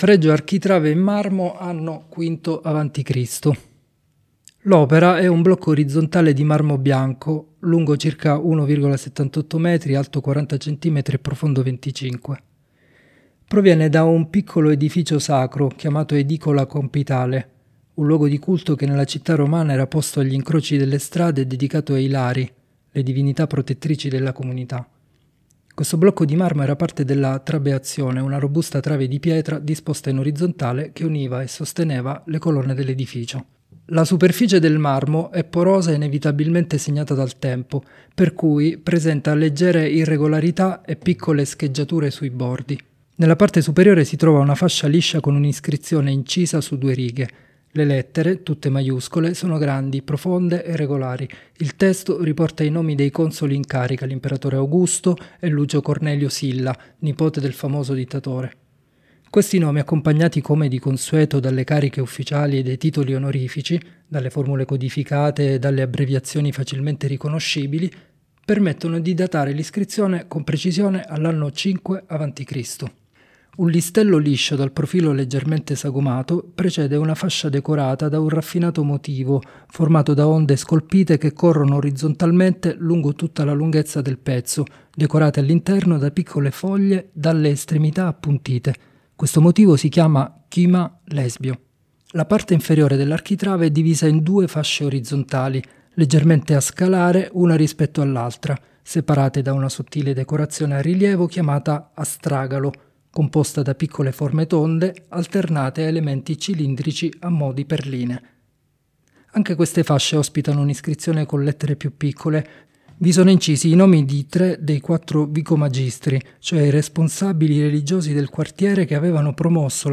Audiodescrizioni sensoriali opere selezionate: